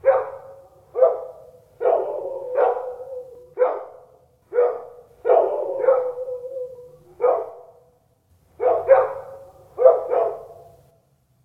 Звук тихий лай собак.